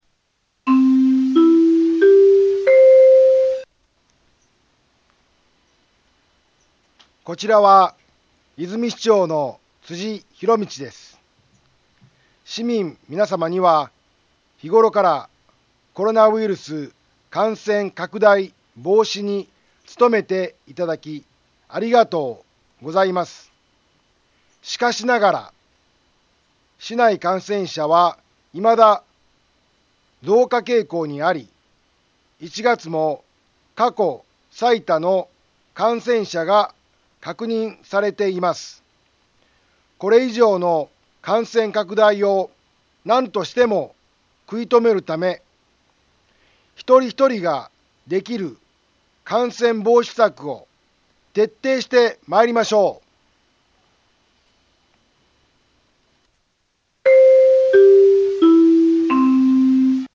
Back Home 災害情報 音声放送 再生 災害情報 カテゴリ：通常放送 住所：大阪府和泉市府中町２丁目７−５ インフォメーション：こちらは、和泉市長の辻ひろみちです。